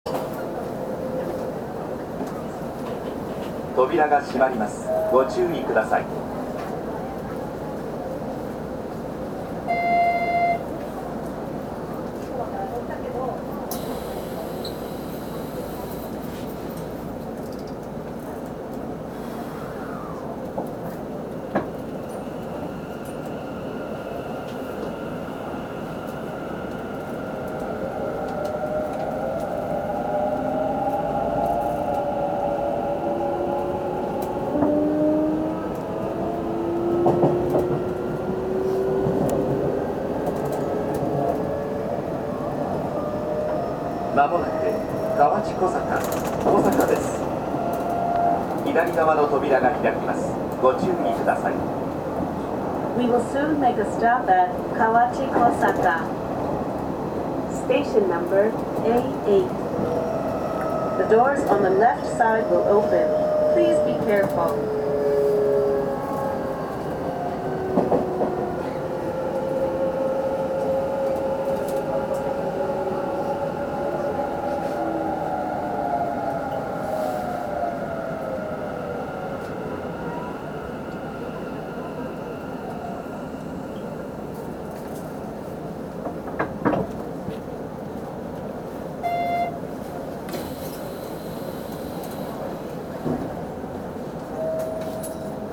走行機器はIGBT素子によるVVVFインバータ制御であり、定格170kWのTDK-6147-A形かご形三相誘導電動機を制御します。
走行音
録音区間：若江岩田～八戸ノ里(お持ち帰り)